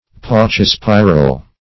Search Result for " paucispiral" : The Collaborative International Dictionary of English v.0.48: Paucispiral \Pau`ci*spi"ral\, a. [L. paucus few + E. spiral.]